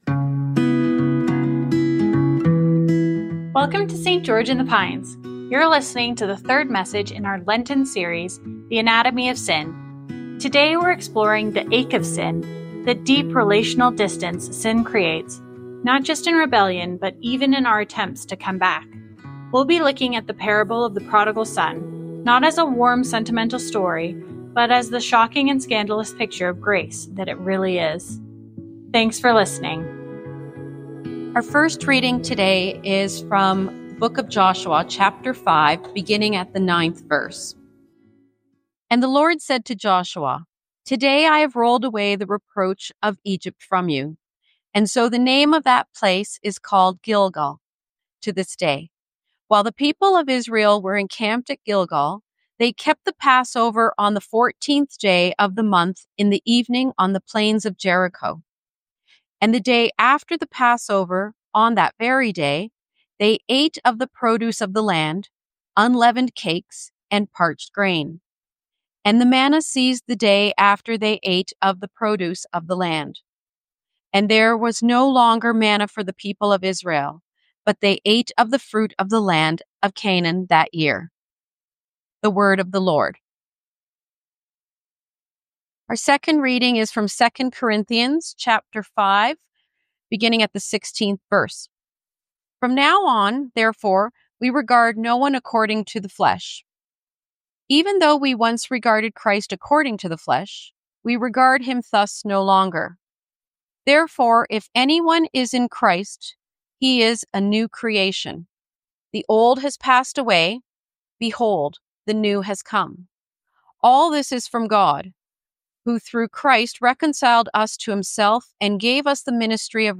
This sermon explores what happens when sin isn’t just about what we’ve done, but about why we come back at all, and what that says about our hearts.